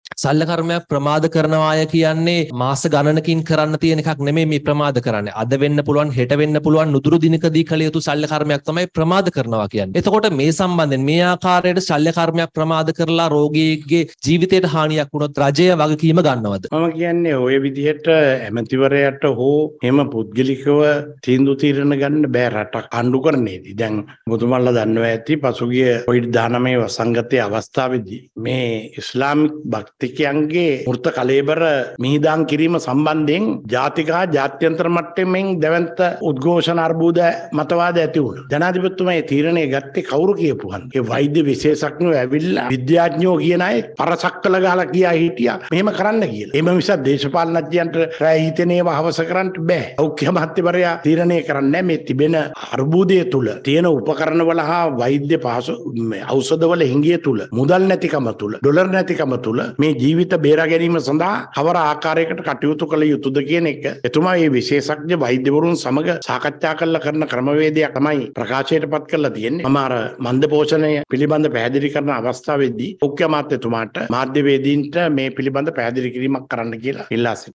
කැබිනට් තීරණ දැනුම් දීමේ මාධ්‍ය හමුවට එක්වෙමින් අමාත්‍ය බන්දුල ගුණවර්ධන මහතා මේ බව පැවසුවා.